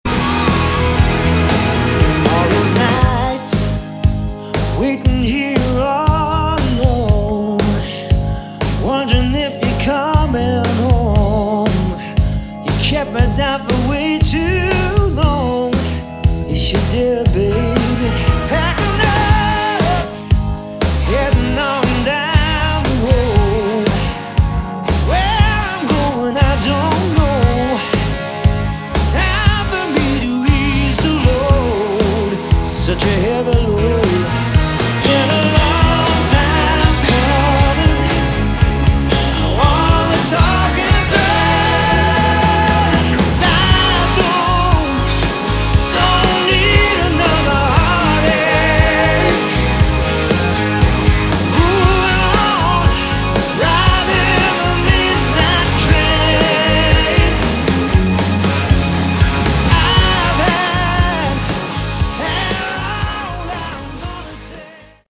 Category: AOR / Melodic Rock
Vocals, Guitar
Bass
Drums
Keyboards